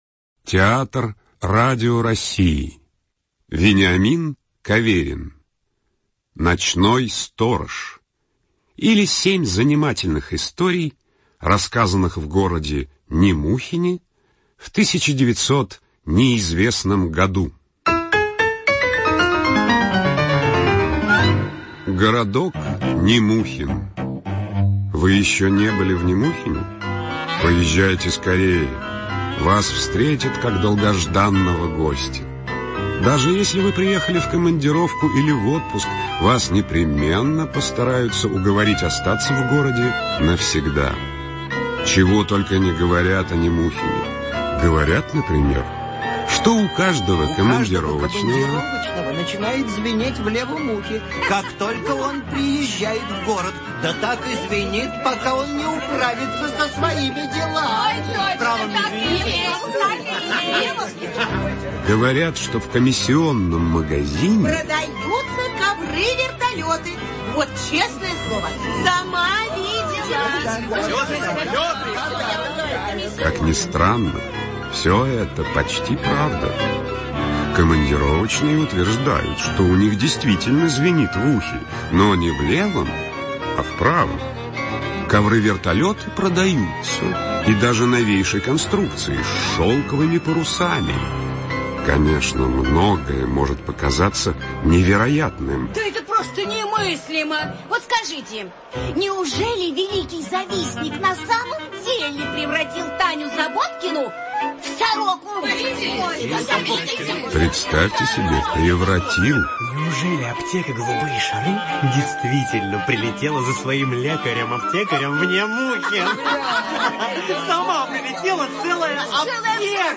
На данной странице вы можете слушать онлайн бесплатно и скачать аудиокнигу "Ночной сторож" писателя Вениамин Каверин.